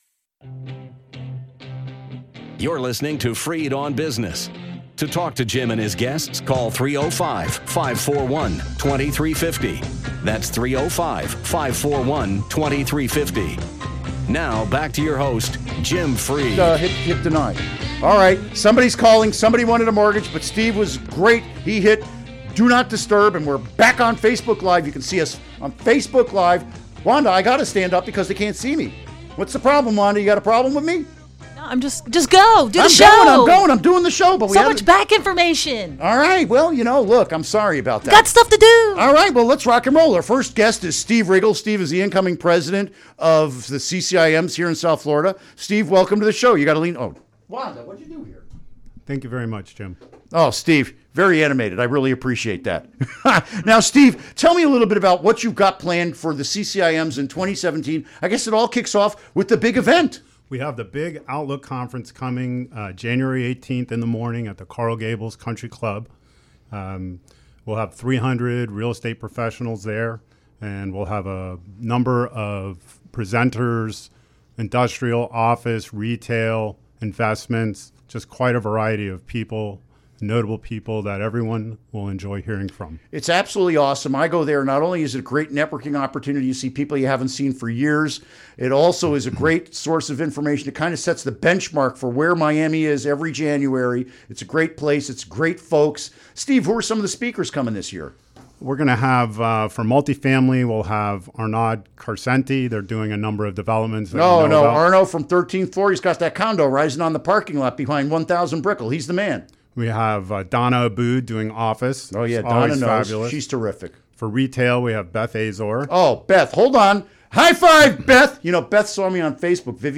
Interview Segment Episode 396: 12-01-16 Download Now!